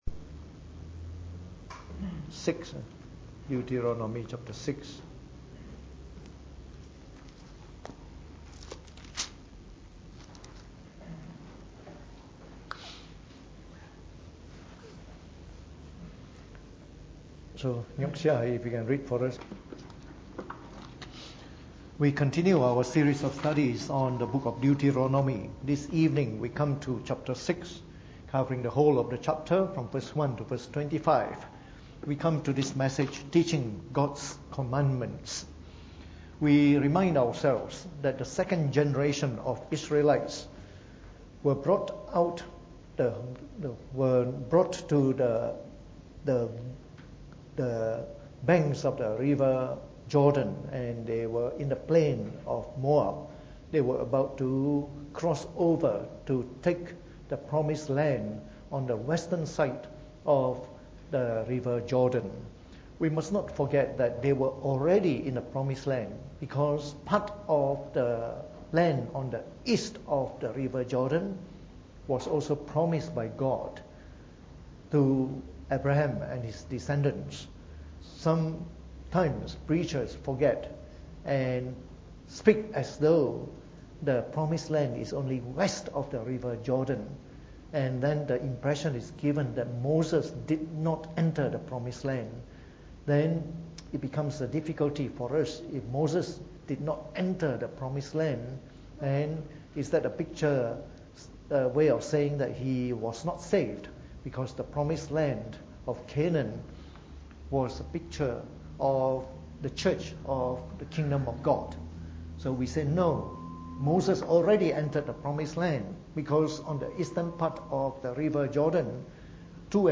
Preached on the 21st of February 2018 during the Bible Study, from our series on the book of Deuteronomy.